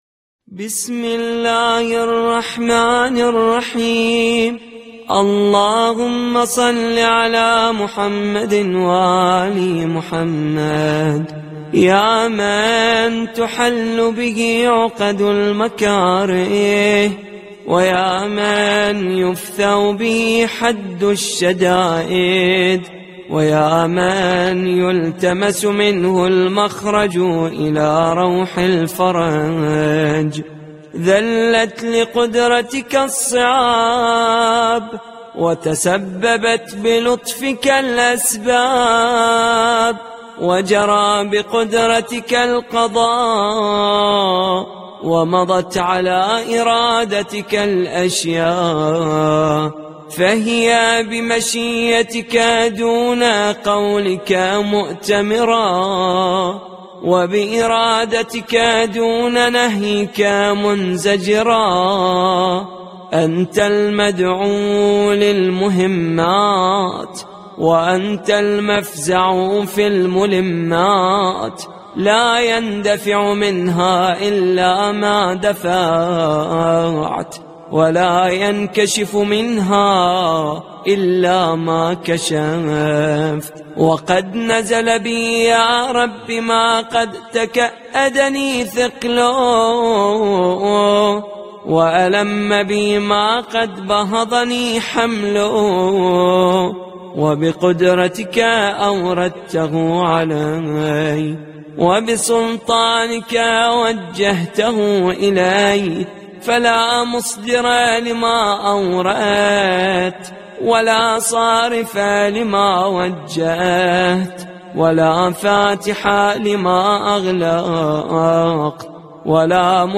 • دعای صوتی, دعای یامن تحل به عقد المکاره, پخش دعا, یامن تحل به عقد المکاره, فایل صوتی